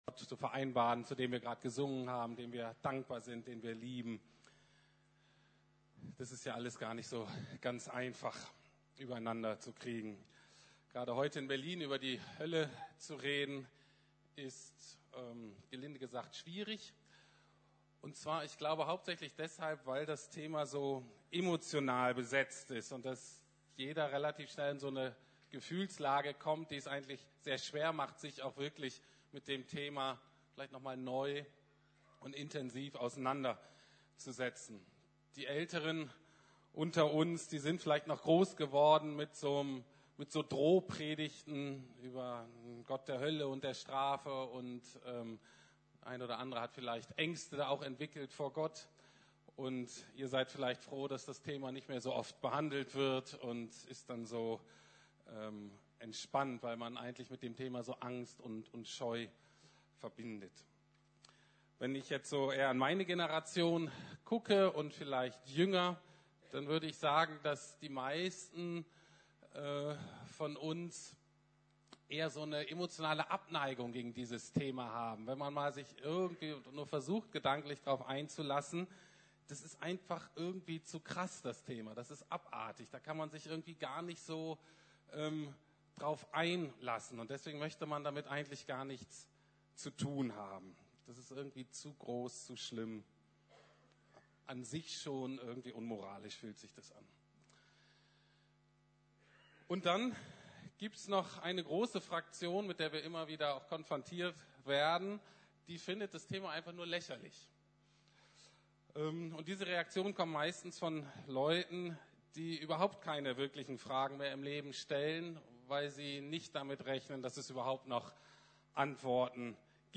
Wie kann ein liebender Gott die Menschen in die Hölle schicken? ~ Predigten der LUKAS GEMEINDE Podcast